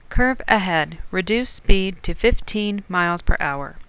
Speech-Warning.wav (669.7 KB)
Both warnings exhibited auditory (speech) and visual (Heads Down Display) stimuli, however one included a throttle pushback haptic stimulus and the other did not.